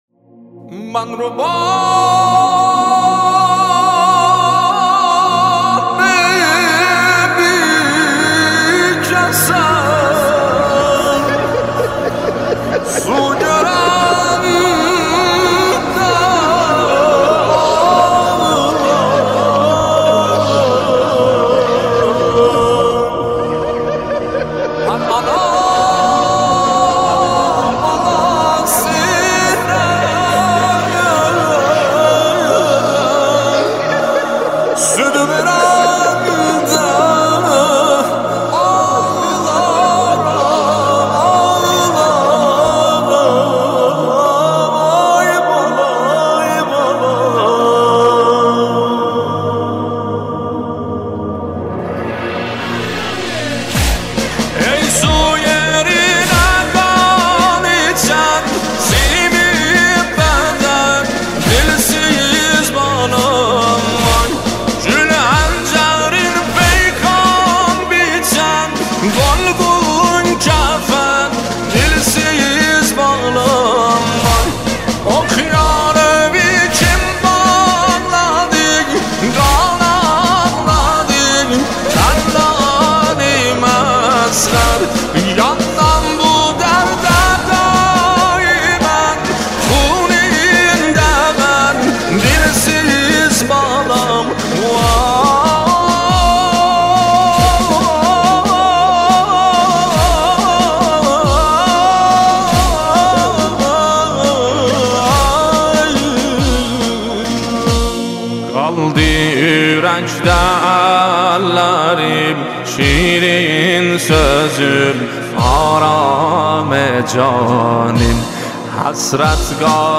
نوحه ترکی